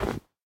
snow1.ogg